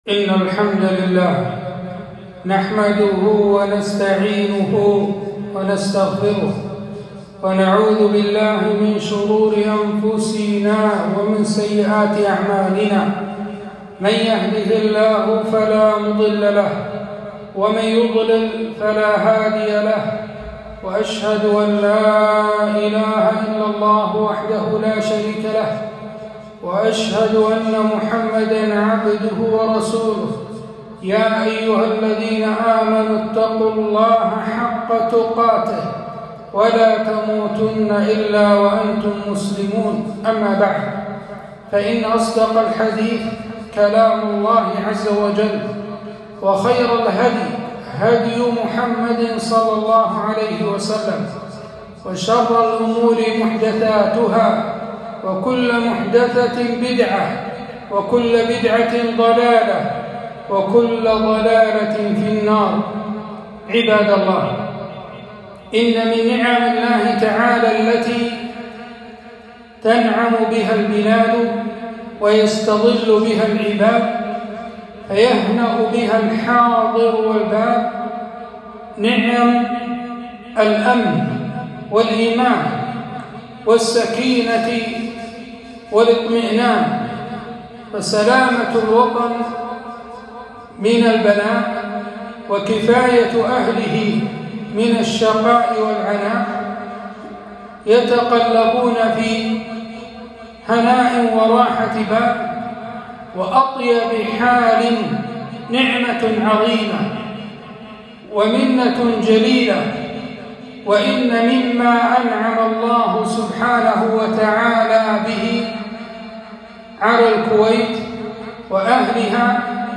خطبة - نعمة الاستقلال والتحرير